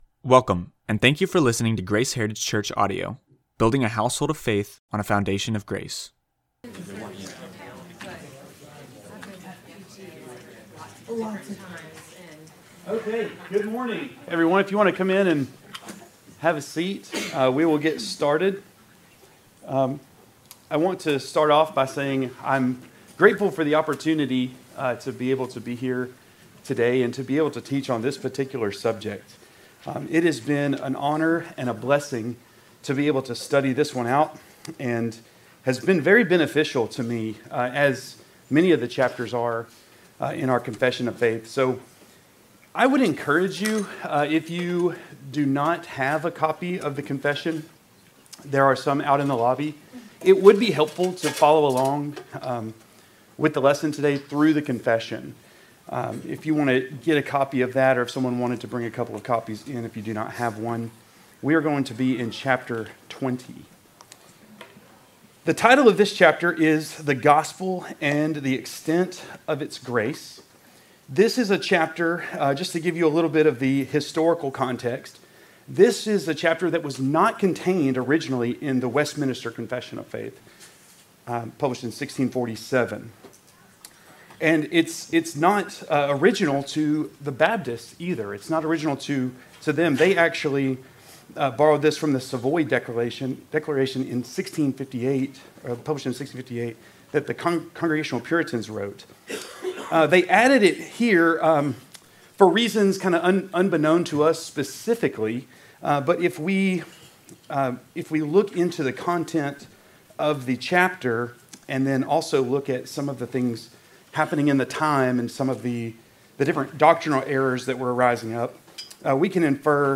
Bible Study